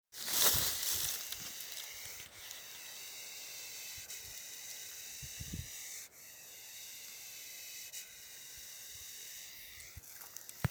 Reptiles -> Snakes ->
adder, Vipera berus